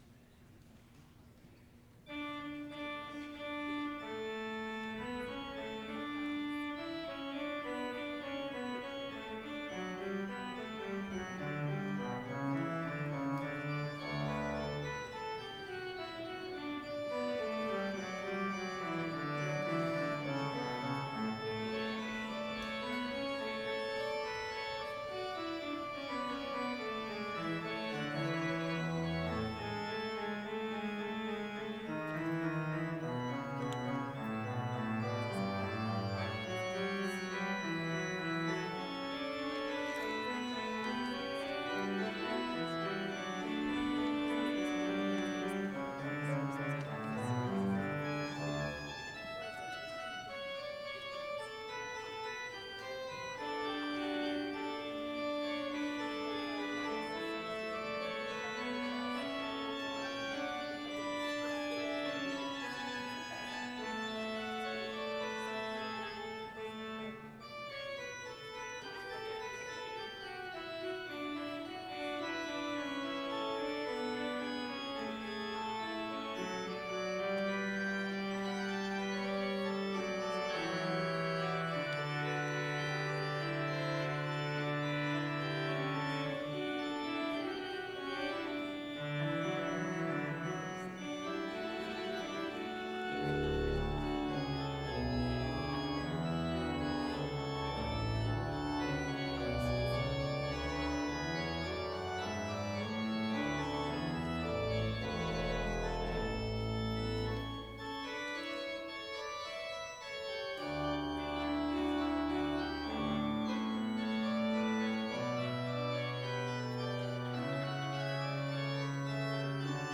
Vespers service on October 25, 2012, at Bethany Chapel in Mankato, MN, (audio available) reading Special Service with None Specified preaching.
Complete service audio for Reformation Vespers - October 25, 2012